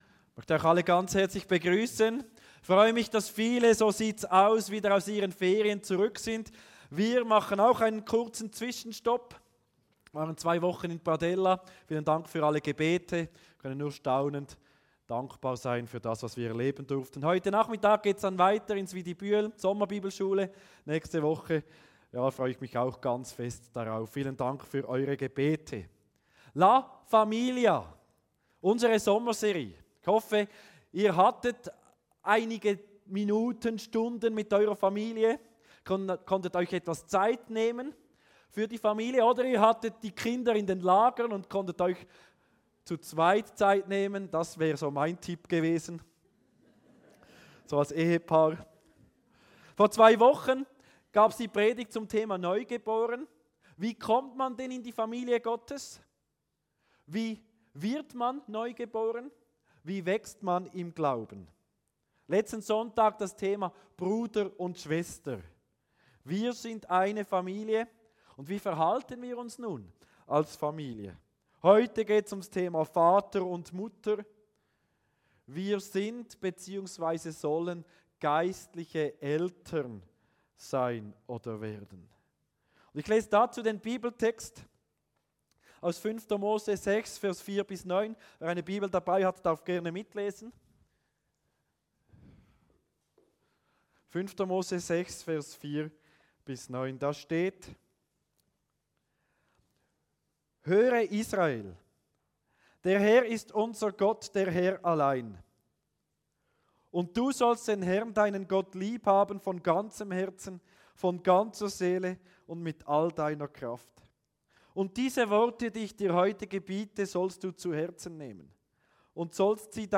Kategorie: Predigt Tags: 5.